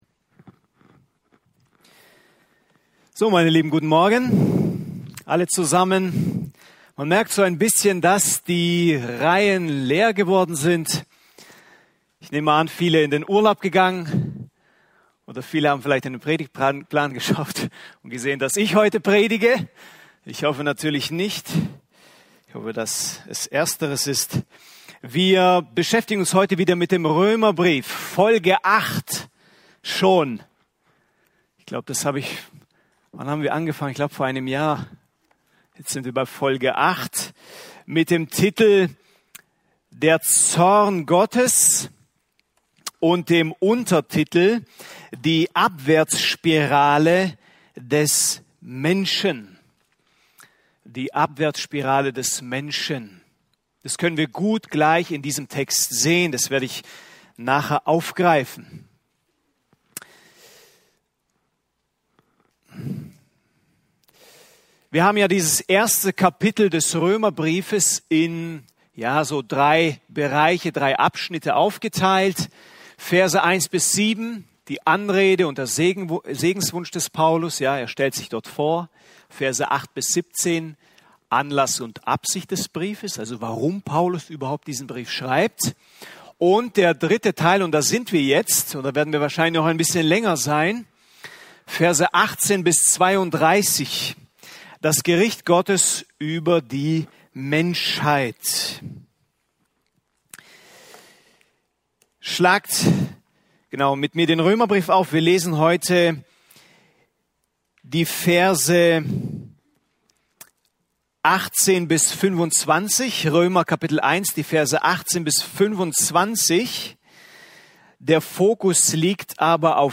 Römer 1,14-17 Dienstart: Predigten Römer Das Herz des Apostels für die Gläubigen in Rom 4.